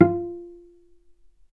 vc_pz-F4-mf.AIF